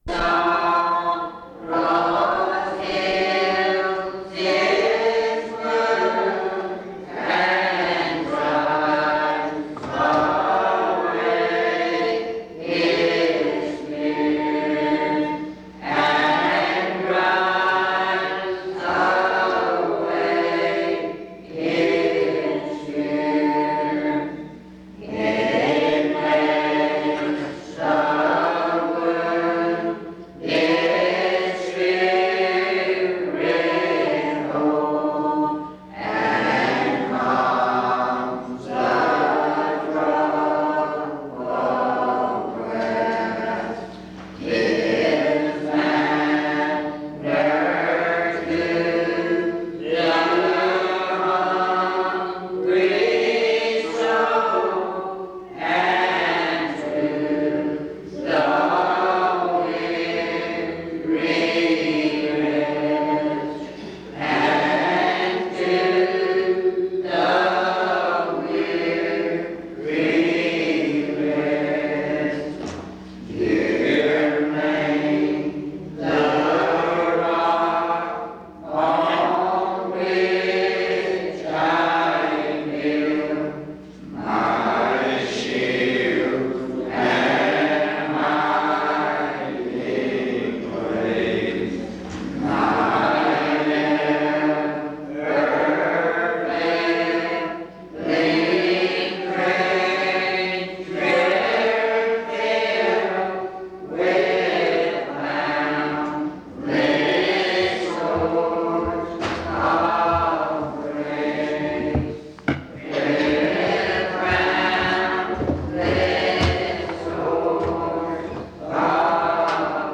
In Collection: Reidsville/Lindsey Street Primitive Baptist Church audio recordings Thumbnail Title Date Uploaded Visibility Actions PBHLA-ACC.001_053-A-01.wav 2026-02-12 Download PBHLA-ACC.001_053-B-01.wav 2026-02-12 Download